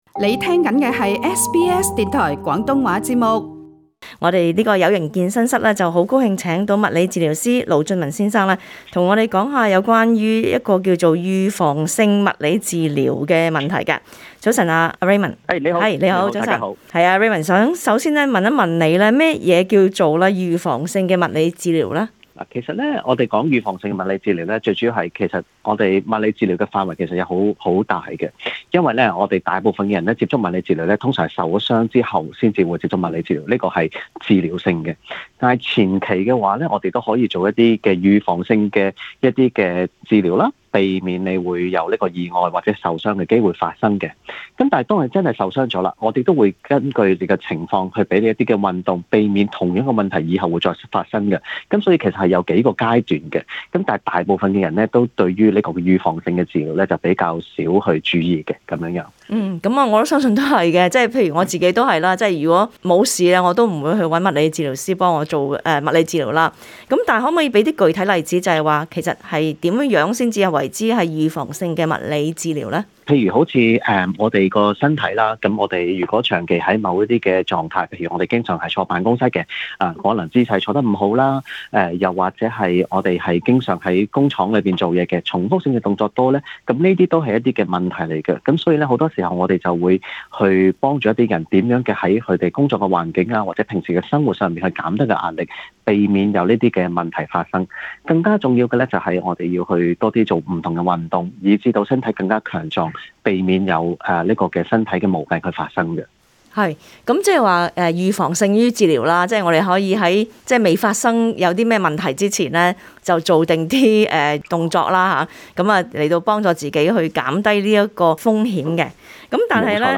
並解答聽眾的問題